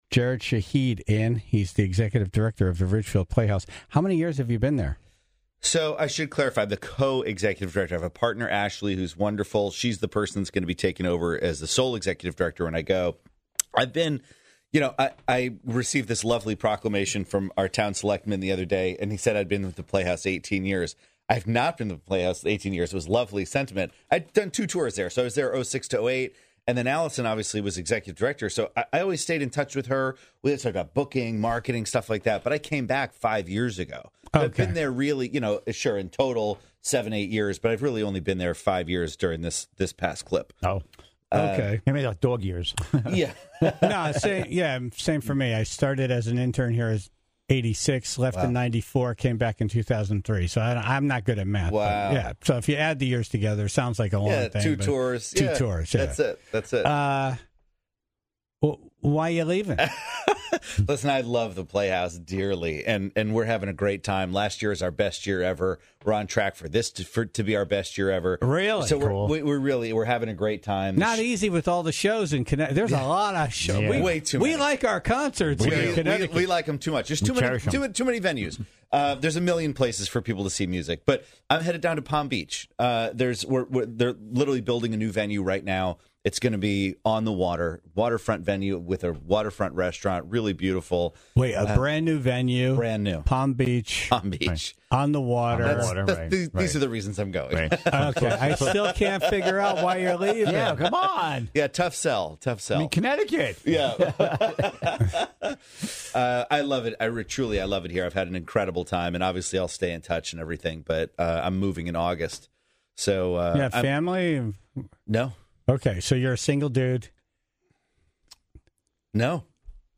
was in studio today